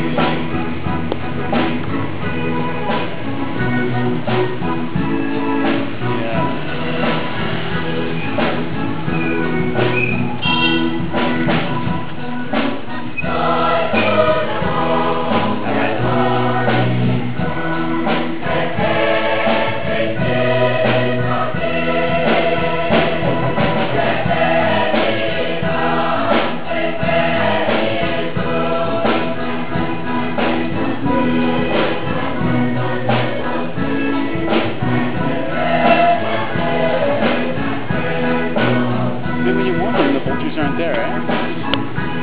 We wake to the sound of carols, backed by the usual church back-up band.